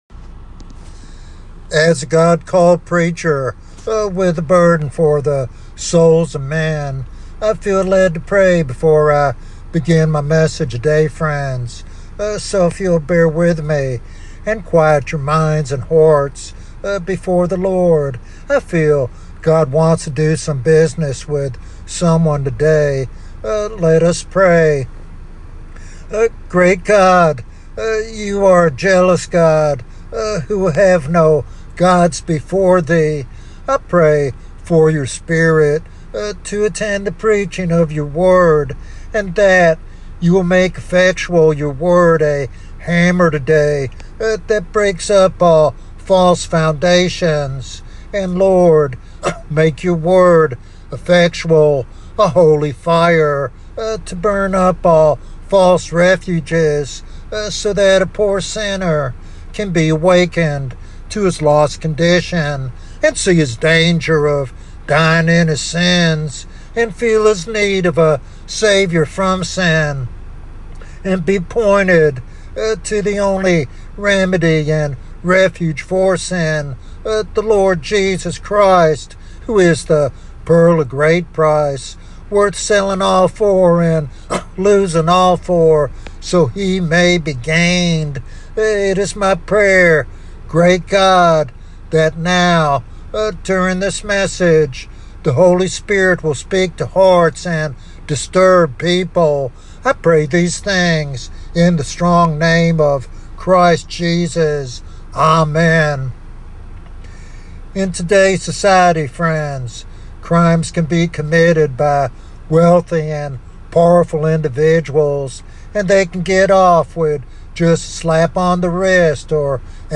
In this powerful evangelistic sermon
He passionately calls listeners to trust in Jesus Christ as the only refuge from eternal punishment. This message challenges believers and unbelievers alike to consider their standing before God and the eternal consequences of sin.